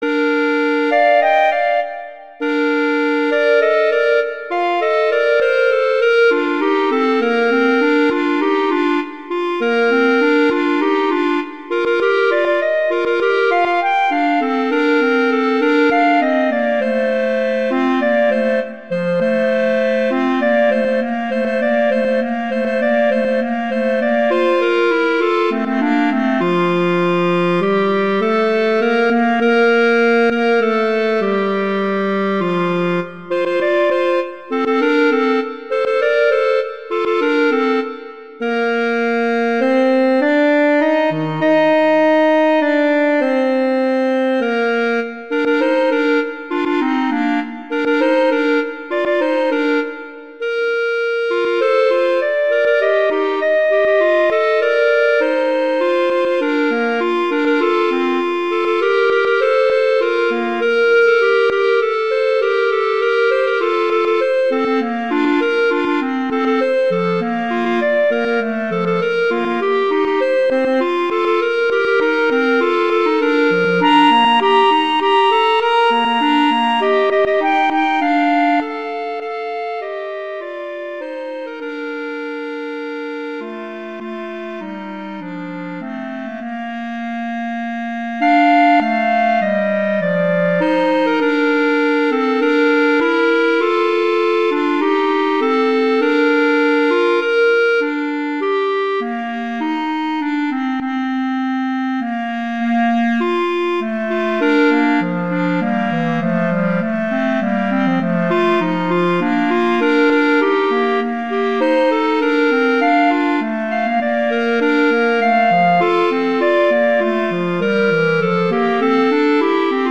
Instrumentation: two clarinets
transcription for two clarinets
classical, sacred
Bb major
♩=100 BPM